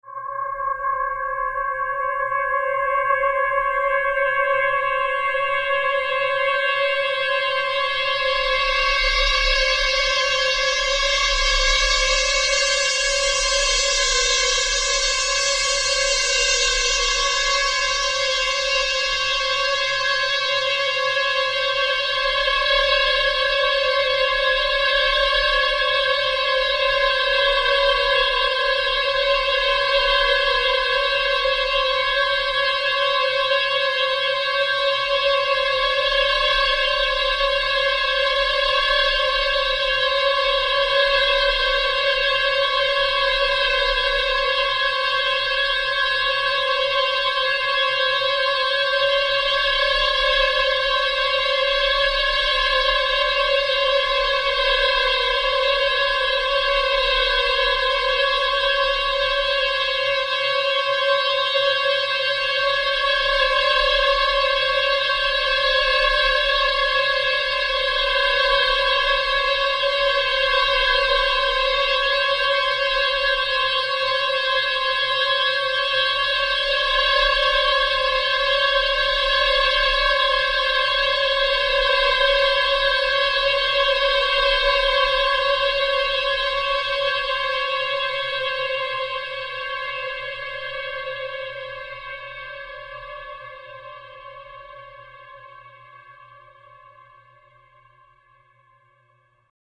Extra Long Sound Effect - 1m 39s
Use This Extra Long Premium Hollywood Studio Quality Sound In Stereo.
Channels: 2 (Stereo)
This Premium Quality Futuristic Sound Effect
A Professional Sounding Extra Long STEREO Sound Effect.
Tags: long large alien beam beams future futuristic laser sci-fi science fiction scifi